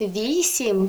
numbers station voice files search 2017-03-22 03:21:06 +00:00 38 KiB Raw History